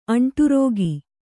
♪ aṇṭurōgi